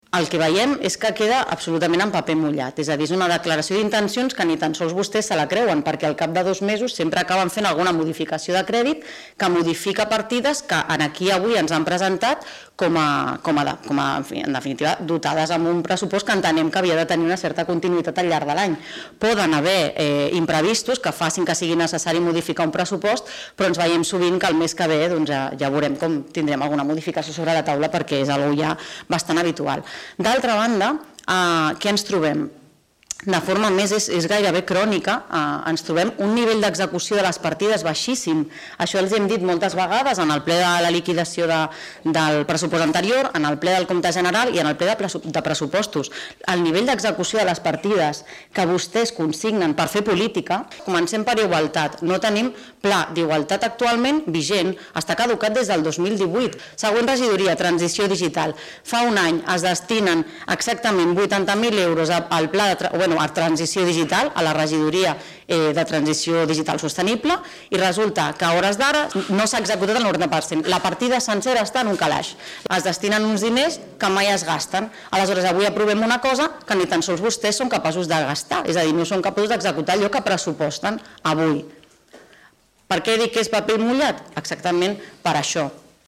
Laura Ruiz, portaveu Movem Martorell
Ple-Municipal-Desembre-06.-Laura-Ruiz.mp3